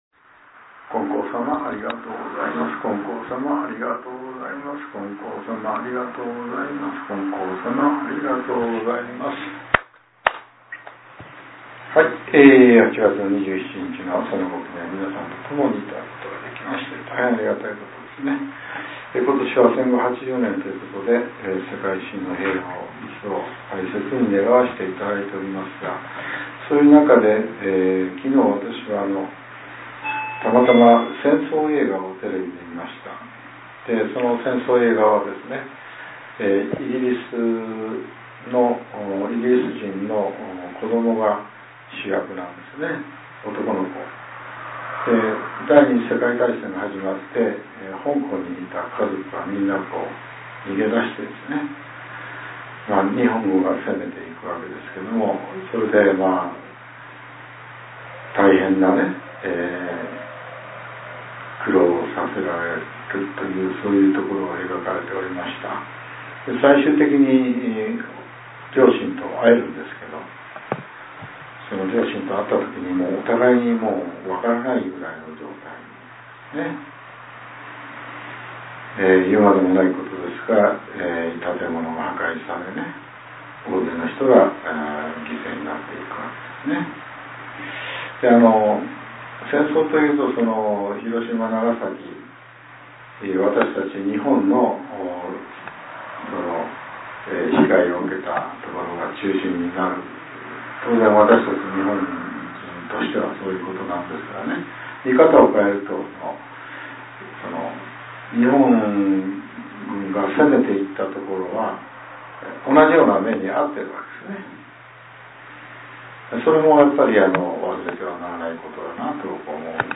令和７年８月２７日（朝）のお話が、音声ブログとして更新させれています。